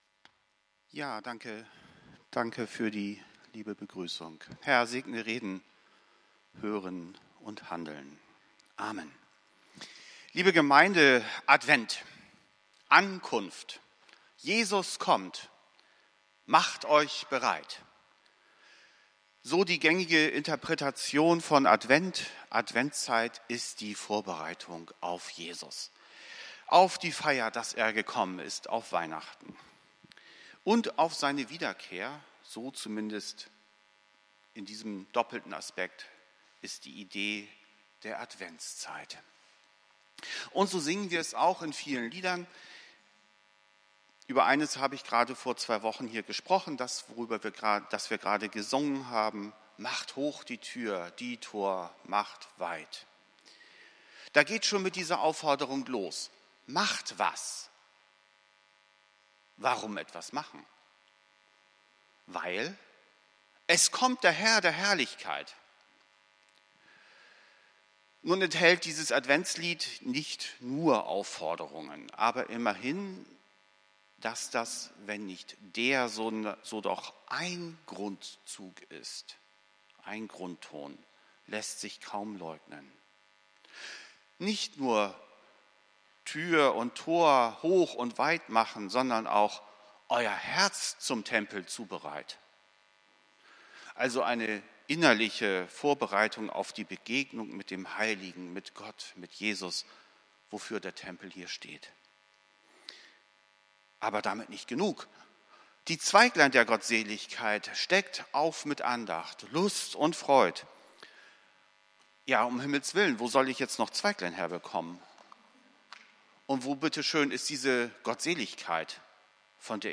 Predigt vom 17.12.2023